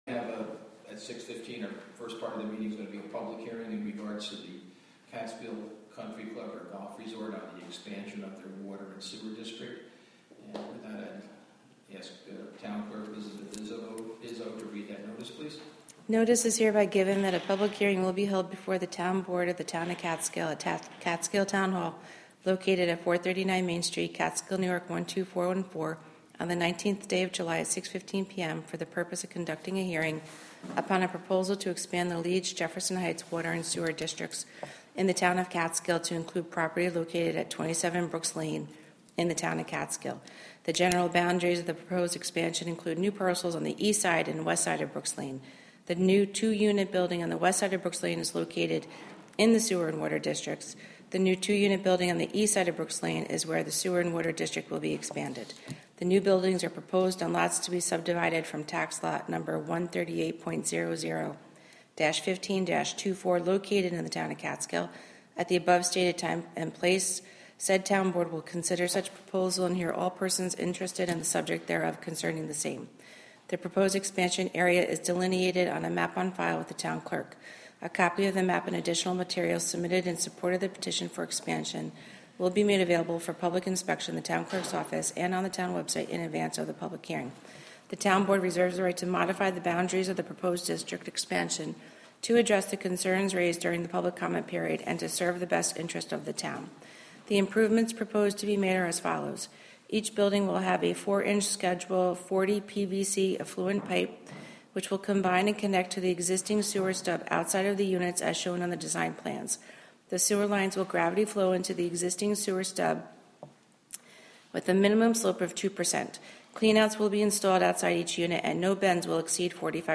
The Town of Catskill holds their monthly meeting.
WGXC is partnering with the Town of Catskill to present live audio streams of public meetings.